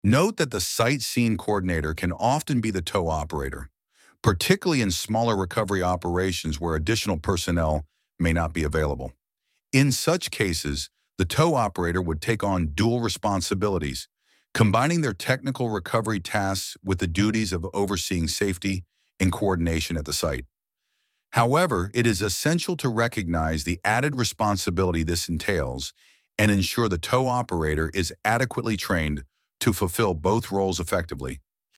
ElevenLabs_Topic_1.6.1.2.mp3